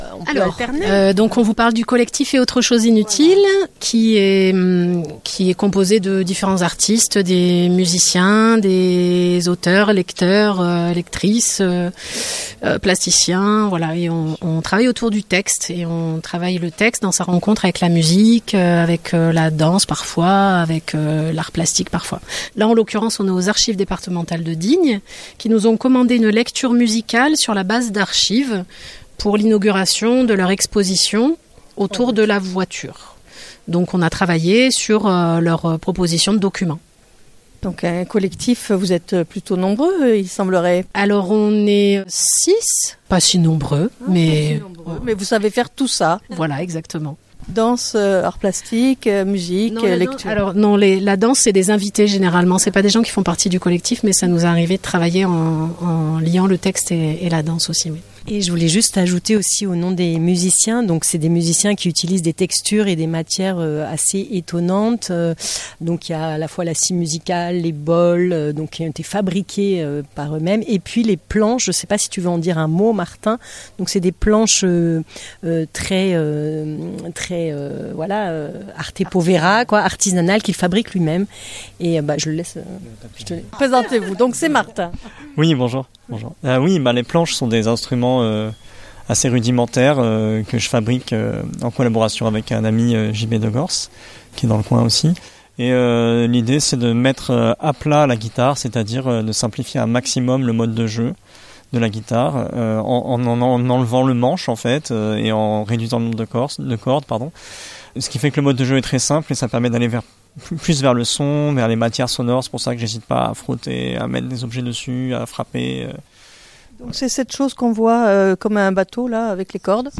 La lecture s'est déroulée juste après l'inauguration de l'exposition de En voiture s'il vous plaît aux archives départementales du 7 décembre 2023 jusqu’à septembre 2025.